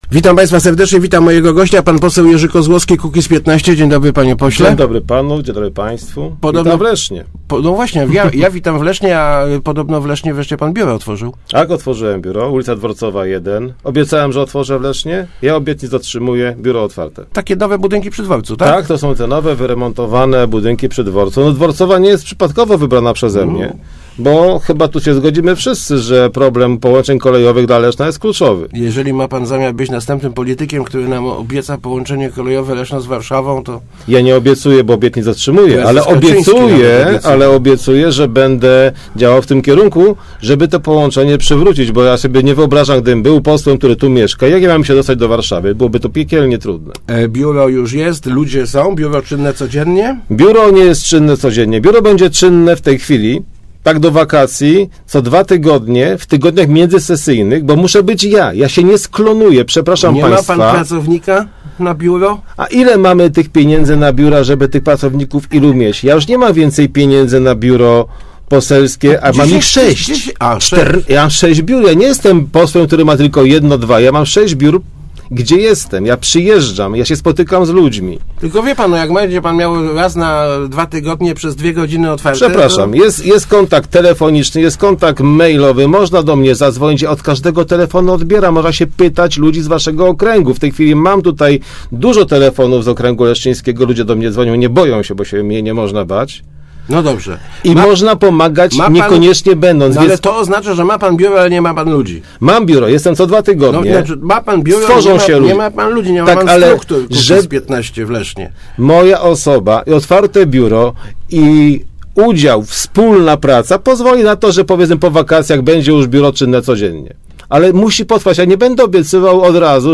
Chcemy być obecni w przyszłym samorządzie Leszna - zapewnił w Rozmowach Elki poseł Jerzy Kozłowski (Kukiz'15). Od kilku dni ma on w Lesznie swoje biuro poselskie, które znajduje się przy ul. Dworcowej.